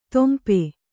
♪ tempe